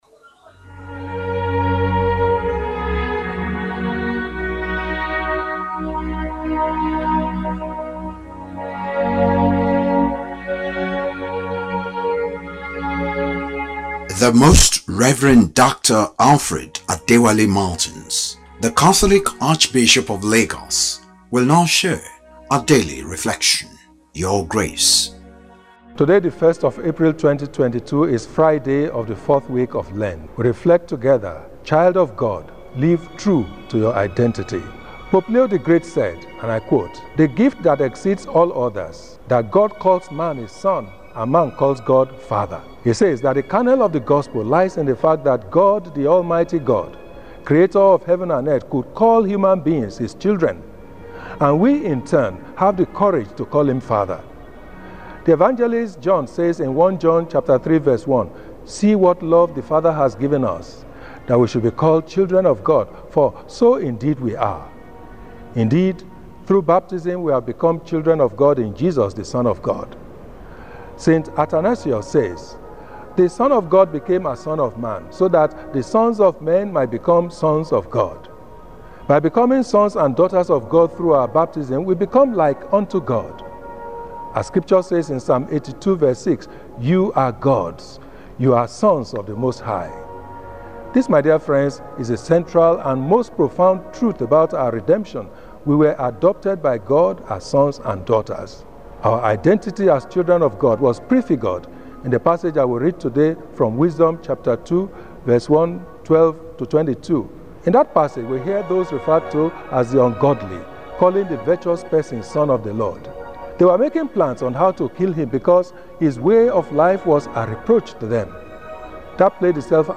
Lenten-Talk-Friday-.mp3